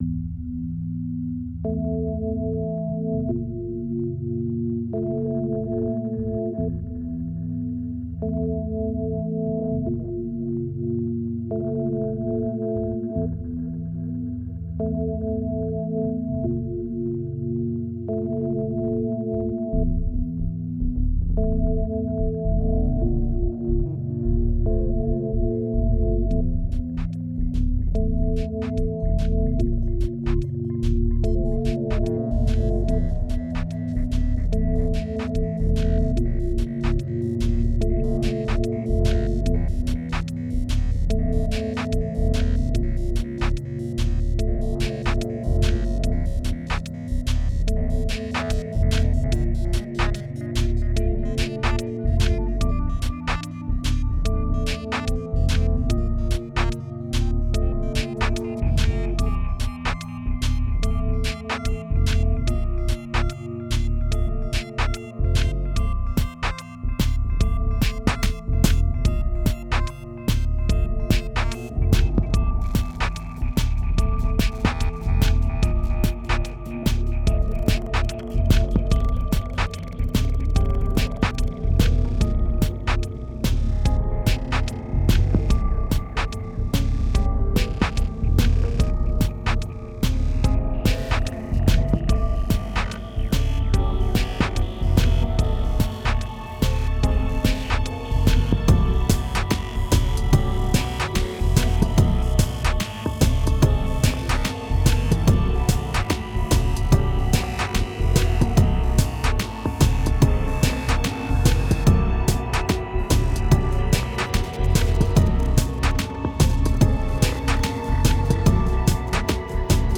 2526📈 - -13%🤔 - 73BPM🔊 - 2011-01-24📅 - -192🌟